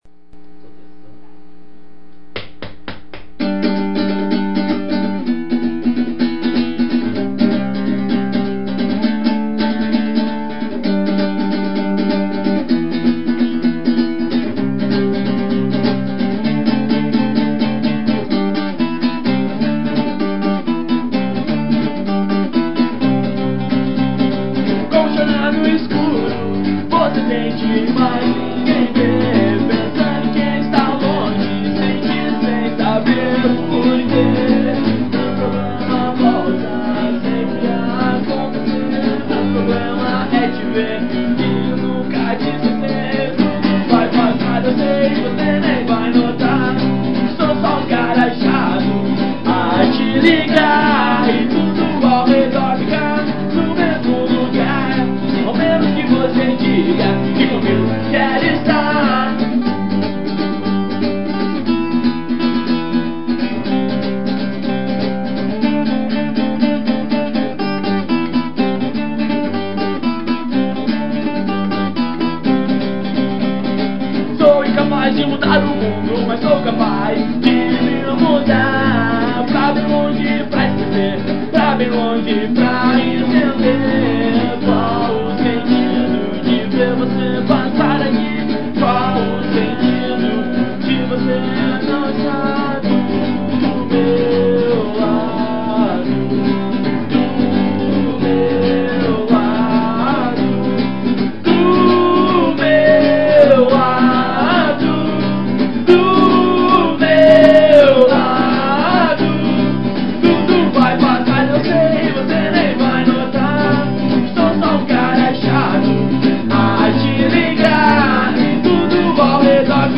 EstiloAlternativo